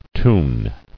[toon]